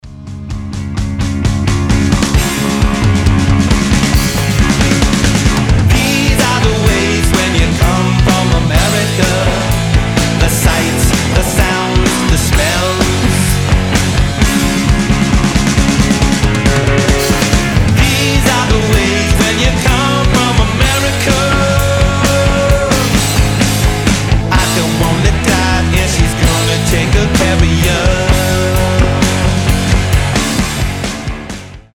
• Качество: 320, Stereo
Alternative Rock
Funk Rock